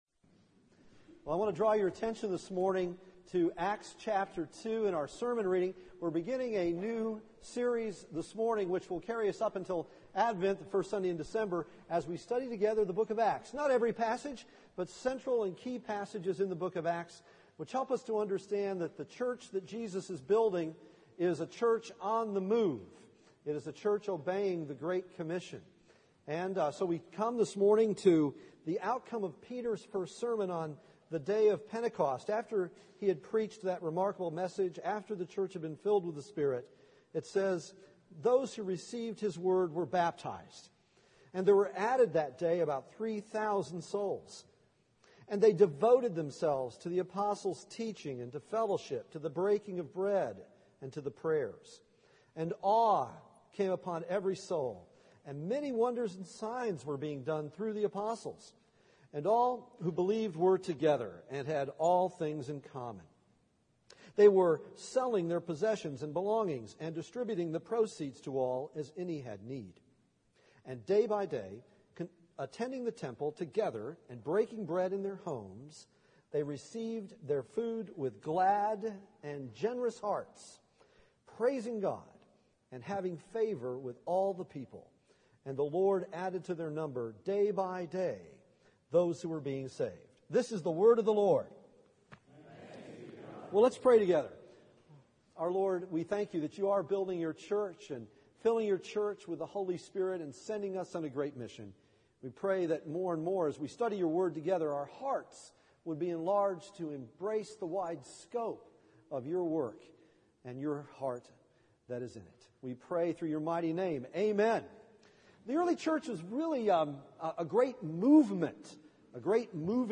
Passage: Acts 2:41-47 Service Type: Sunday Morning « Broken Peaces